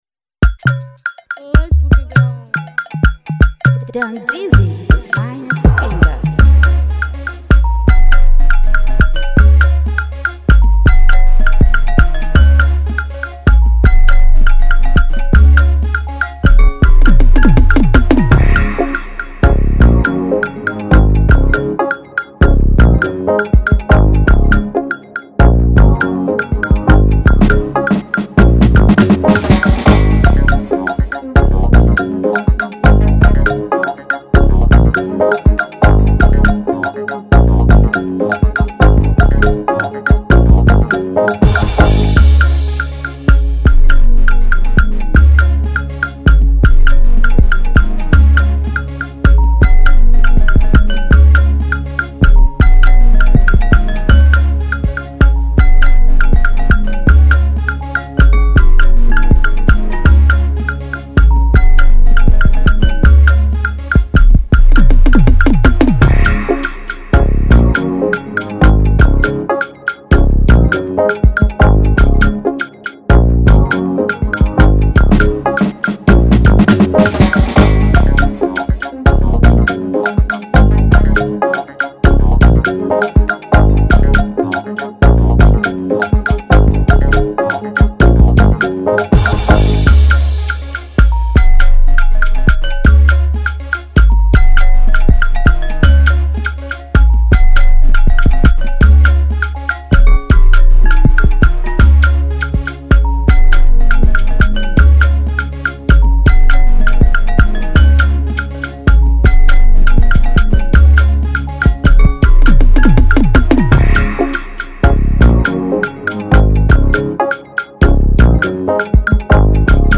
here is a fire afrobeat Gyrations sound